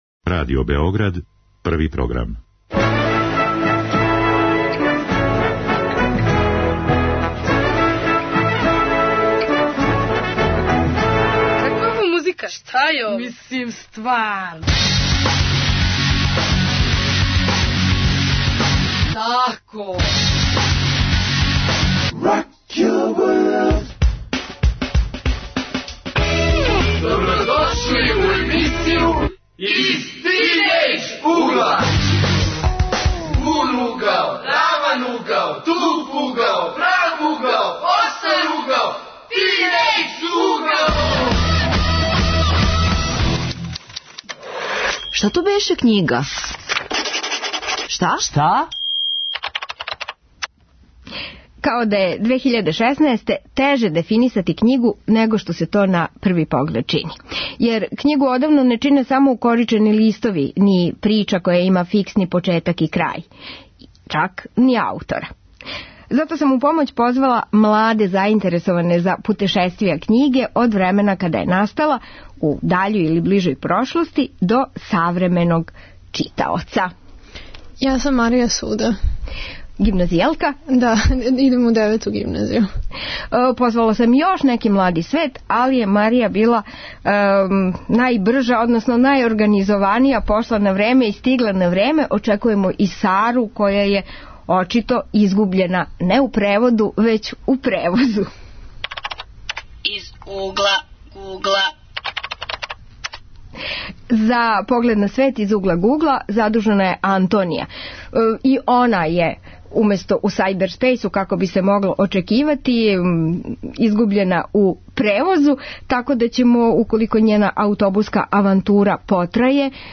Тема ових тинејџерских разговора јесу дела великих писаца адаптирана за филм, позориште или пренета из драмске форме у романе.